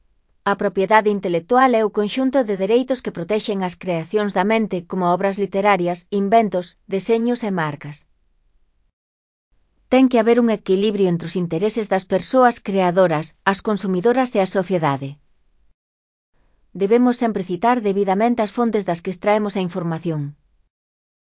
Elaboración propia (Proxecto cREAgal) con apoio de IA, voz sintética xerada co modelo Celtia. A propiedade intelectual (CC BY-NC-SA)